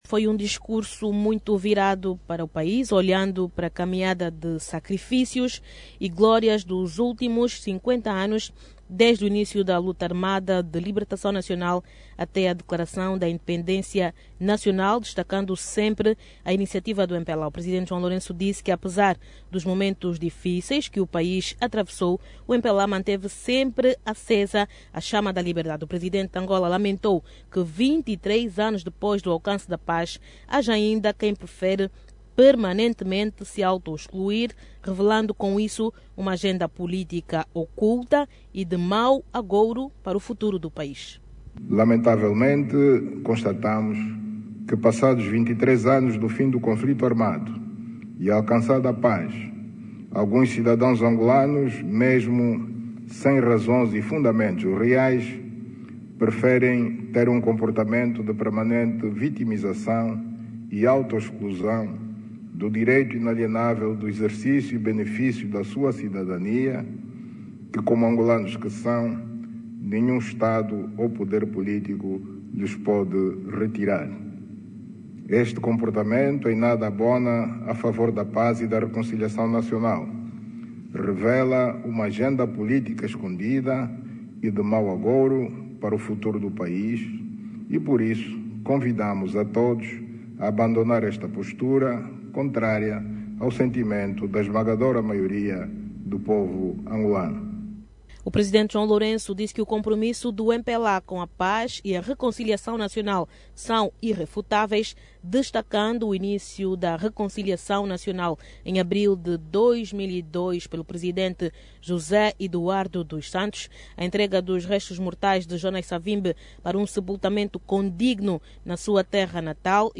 A declaração foi feita na manhã de hoje, durante a abertura da oitava reunião ordinária do Comité Central do MPLA, onde o Presidente saudou as diferentes iniciativas que contribuem para a consolidação da paz.